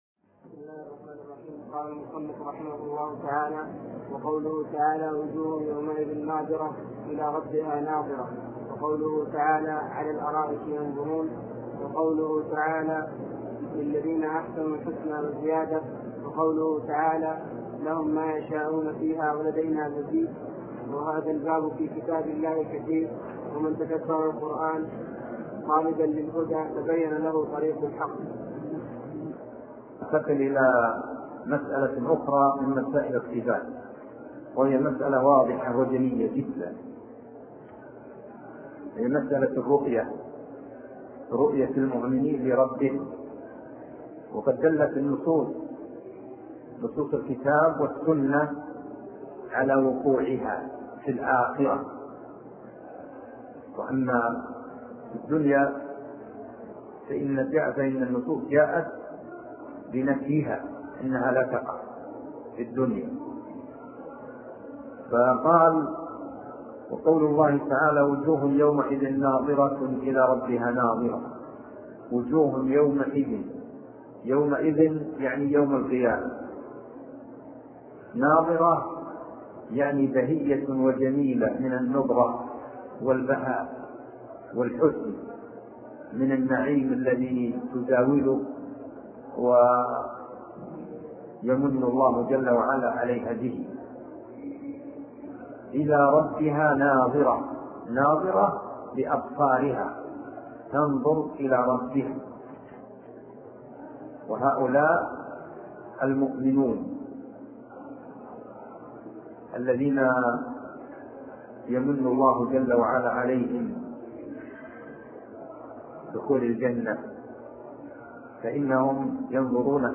عنوان المادة الدرس (7) شرح العقيدة الواسطية تاريخ التحميل الجمعة 3 فبراير 2023 مـ حجم المادة 18.77 ميجا بايت عدد الزيارات 228 زيارة عدد مرات الحفظ 129 مرة إستماع المادة حفظ المادة اضف تعليقك أرسل لصديق